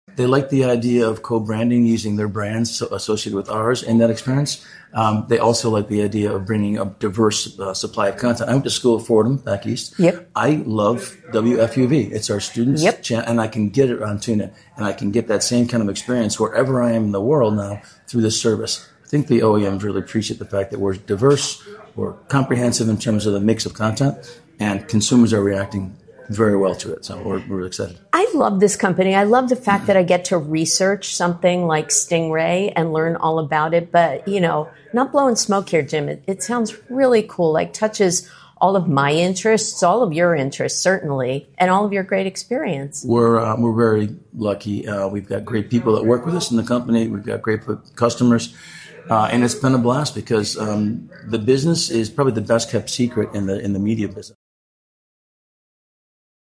Insider Interviews